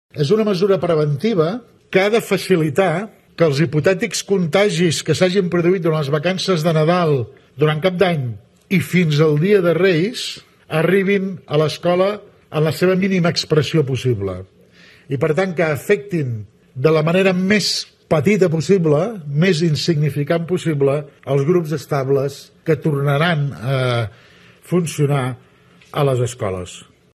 Explicava els motius de la mesura el conseller d’educació, Josep Bargalló.